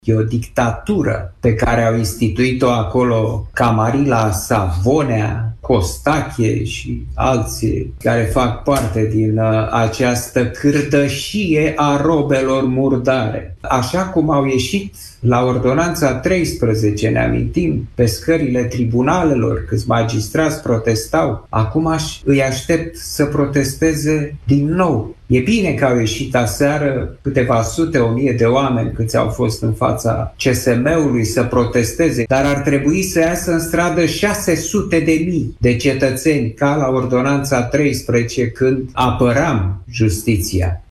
Într-o intervenție la Europa FM, acesta a condamnat actualul sistem de justiție și a salutat protestele de aseară.